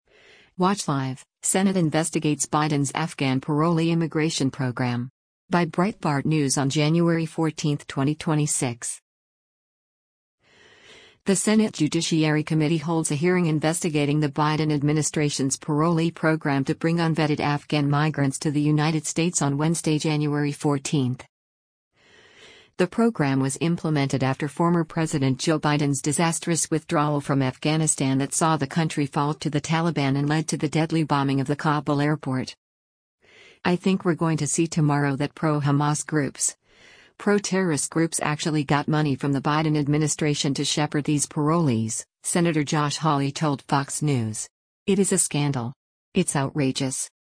The Senate Judiciary Committee holds a hearing investigating the Biden administration’s parolee program to bring unvetted Afghan migrants to the United States on Wednesday, January 14.